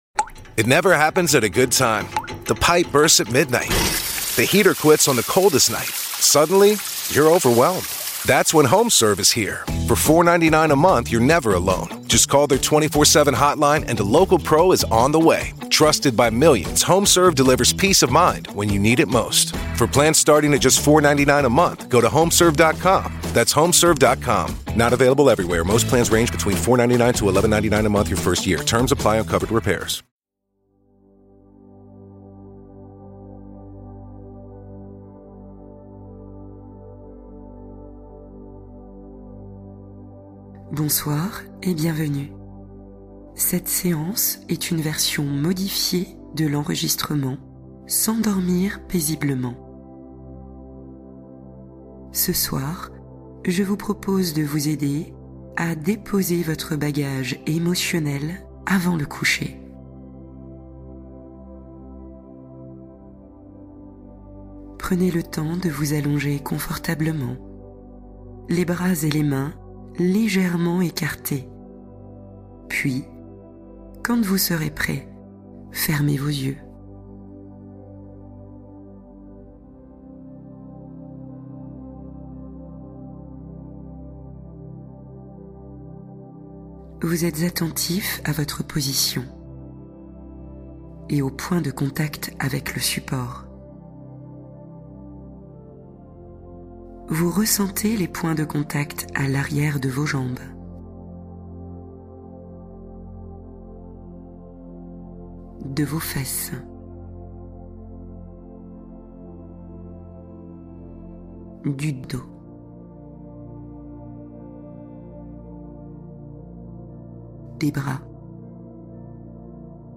Glissement Nocturne : Relaxation progressive pour un endormissement fluide